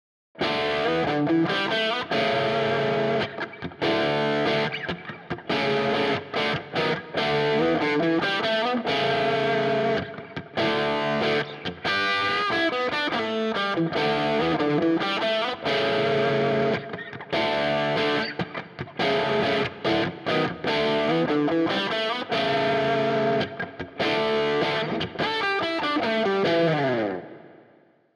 Ihre Stärke liegt hierbei in eher bluesigen und vor allem rockigen Sounds.
Verzerrt, Bridge Humbucker